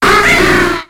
Cri de Cornèbre dans Pokémon X et Y.